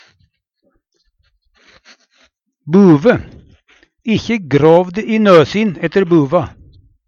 buve - Numedalsmål (en-US)
Tilleggsopplysningar - kan si i nøsin eller nøsøn (to nasebor)